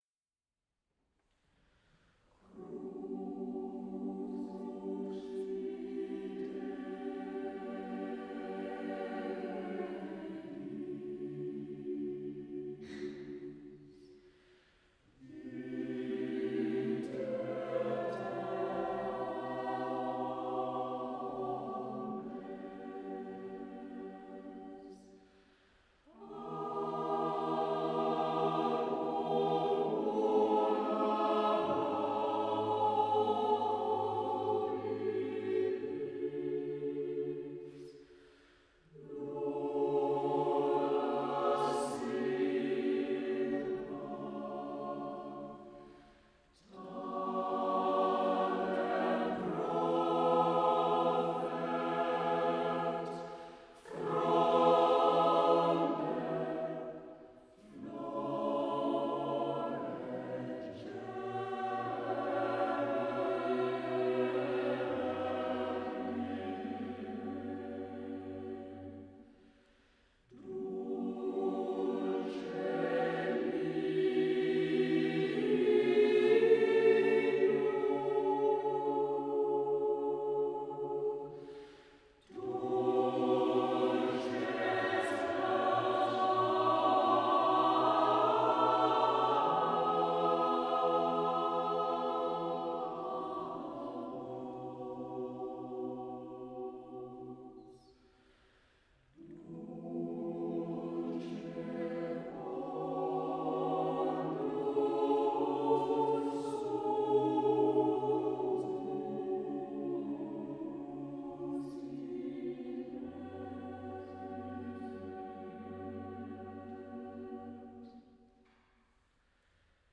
Cherwell Singers; Merton College, Oxford
Ambisonic order : H (3 ch) 1st order horizontal Recording device : MOTU Traveler
Array type : Native B-format Capsule type : AKG Blue Line